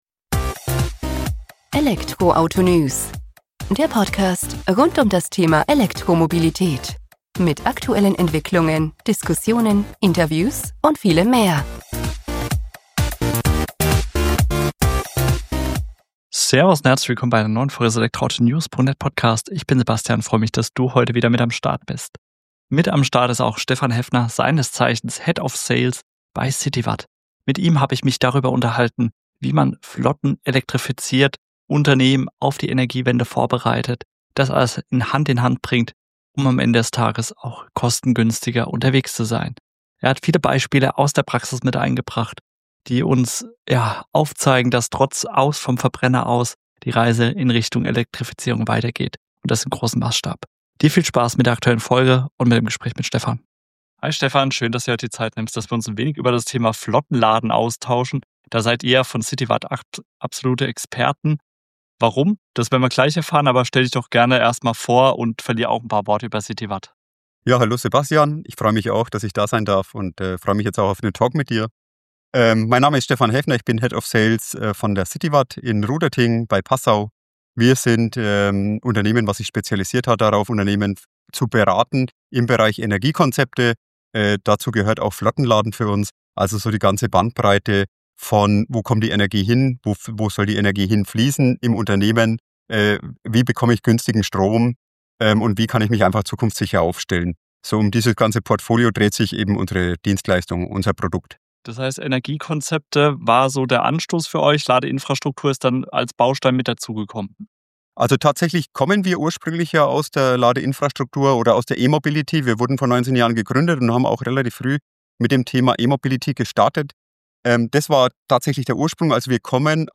Jetzt aber genug der Vorworte – lasst uns direkt in das Gespräch einsteigen.